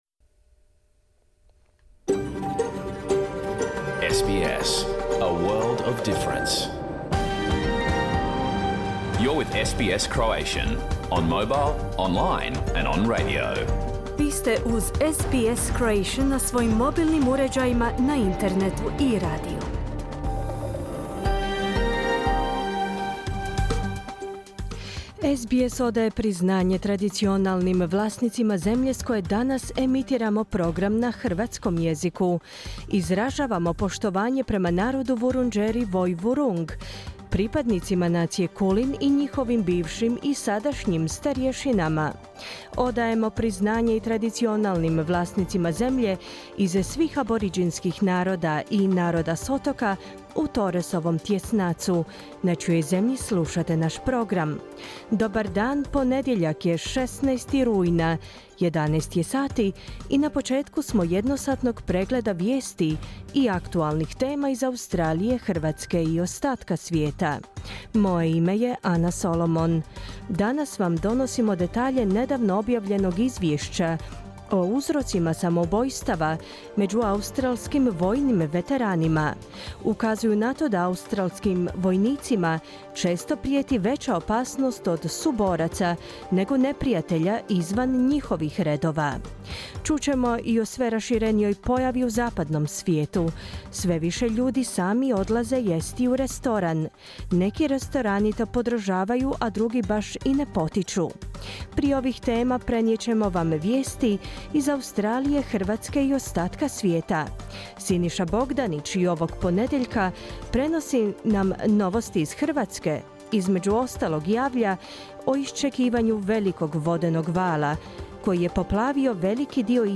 Vijesti i aktualne teme iz Australije, Hrvatske i ostatka svijeta za ponedjeljak, 16. rujna. Program je emitiran uživo na radiju SBS1 u 11 sati, po istočnoaustralskom vremenu.